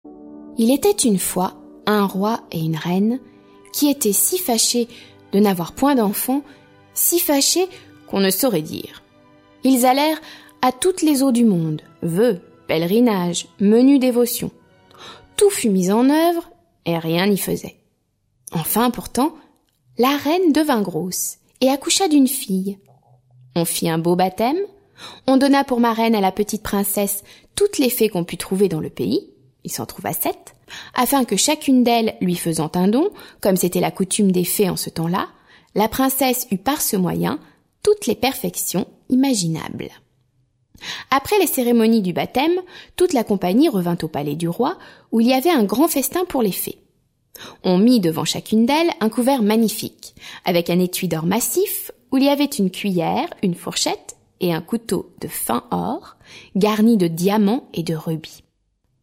Diffusion distribution ebook et livre audio - Catalogue livres numériques
Musique : Delibes (Coppelia, prélude) et Berlioz (Symphonie fantastique)